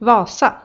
Uttal